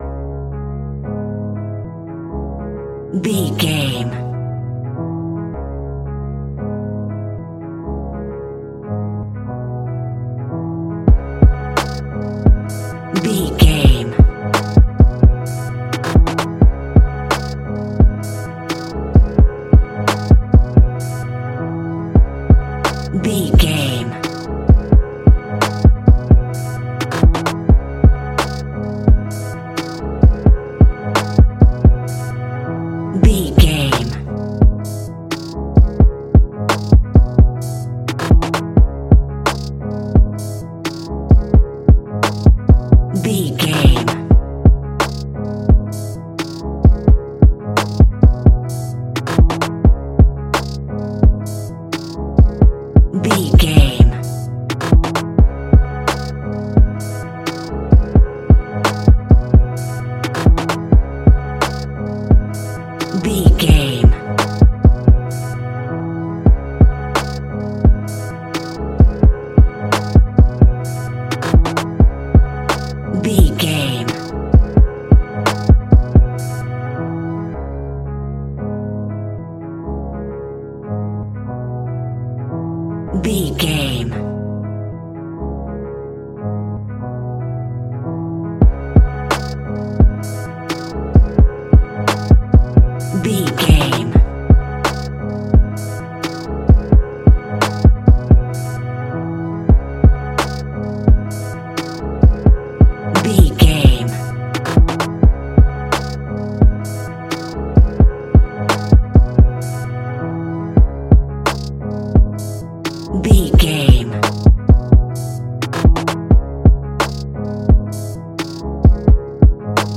Ionian/Major
aggressive
intense
driving
bouncy
energetic
dark
drums